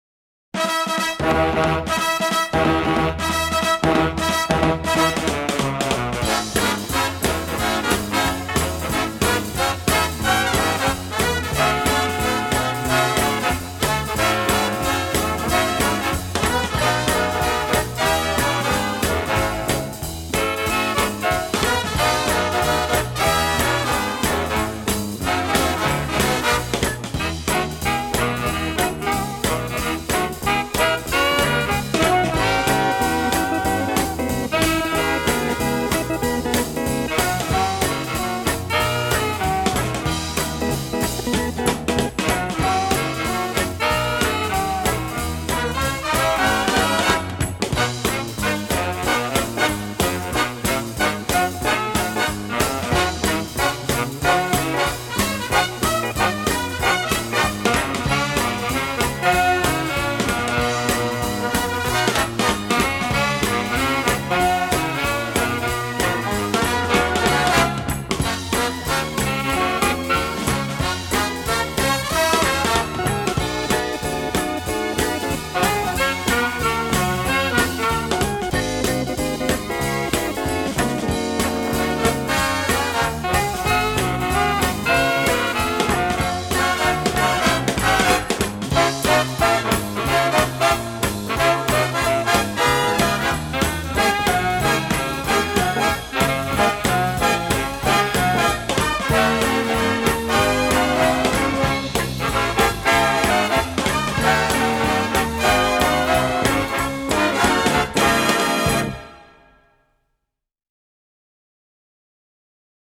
• ノリが跳ねるような感じ: 「バウンス」と呼ばれる、軽快で跳ねるようなリズムが特徴です。